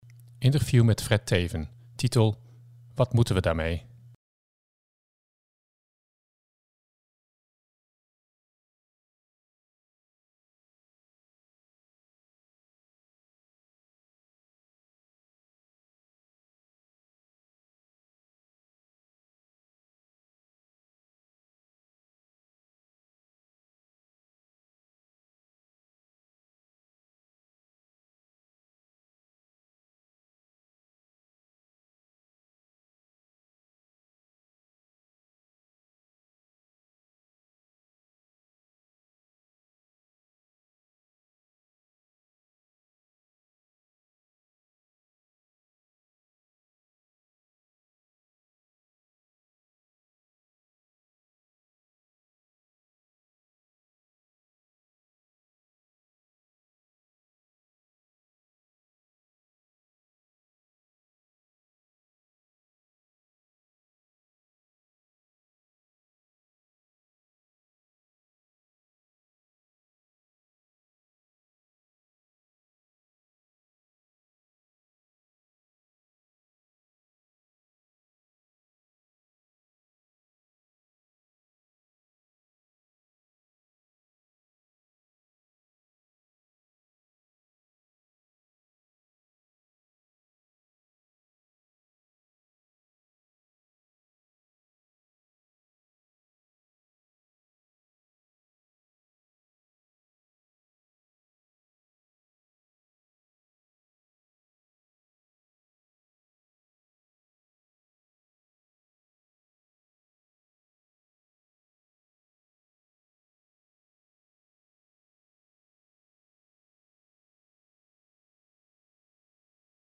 Interview met Fred Teeven.